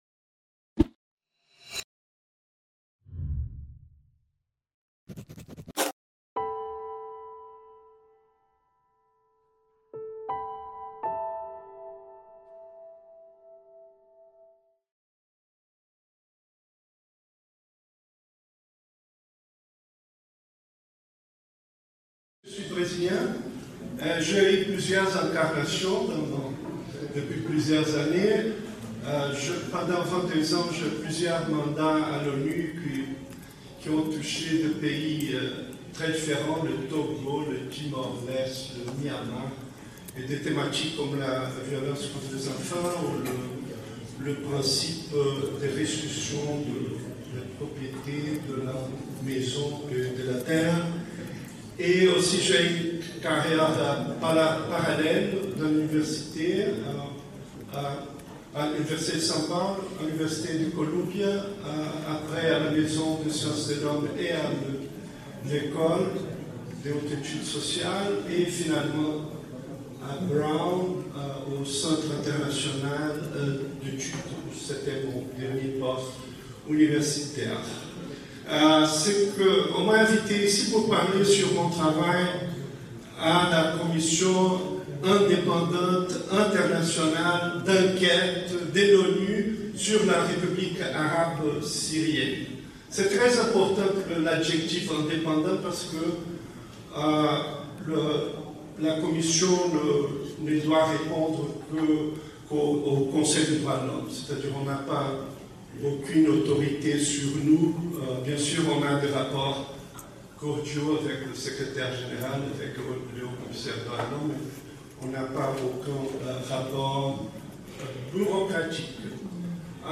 professeur émérite d'anthropologie au Dartmouth College Colloque de Tunis du 3-4 Juillet 2017 Le Panel International sur la Sortie de la Violence s’est réuni à Tunis le 3 et 4 Juillet 2017.